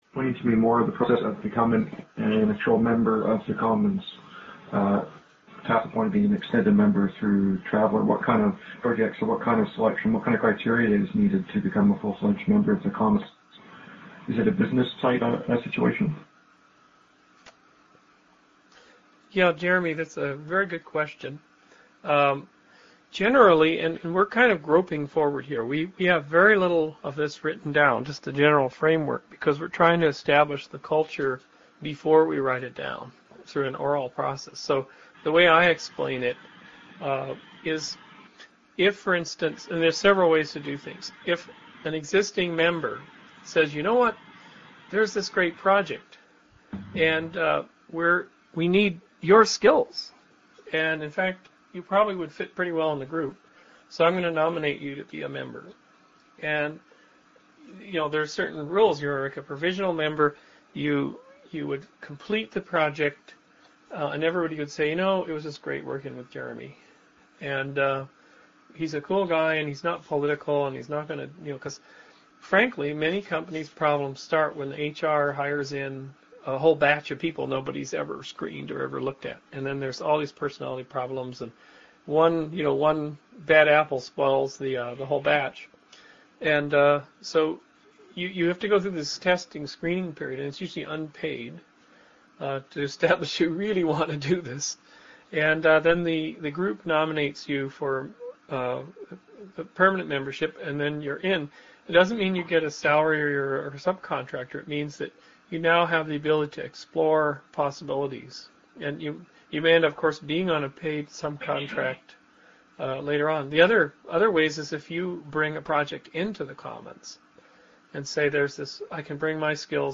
Contact Consortium: Travatars 2004 Report: MP3s of speeches and Travelers